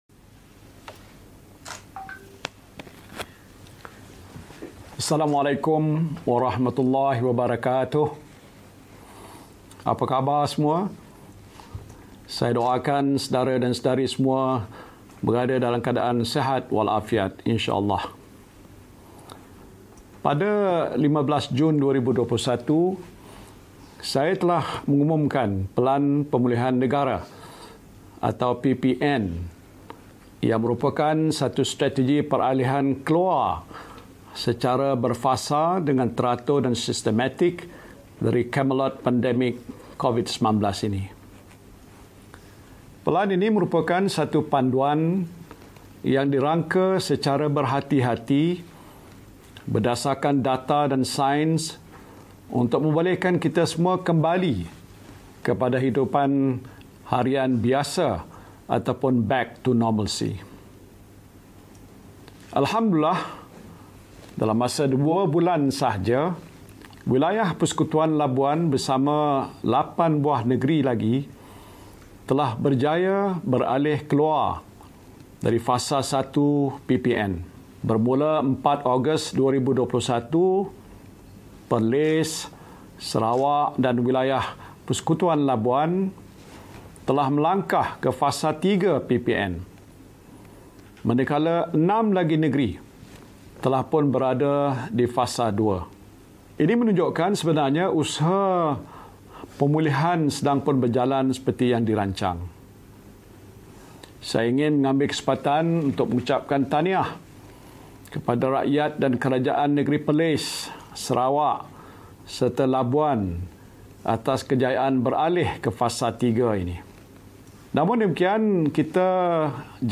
Pengumuman Khas Perdana Menteri
Pengumuman Khas Perdana Menteri, Tan Sri Muhyiddin Yassin berkenaan dengan kemudahan-kemudahan bagi individu yang telah menerima vaksinasi lengkap.